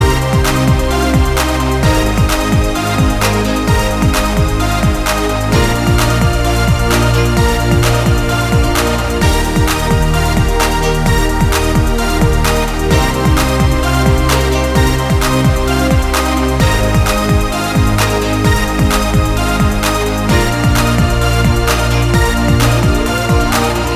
Justin Part Only Pop (2010s) 3:34 Buy £1.50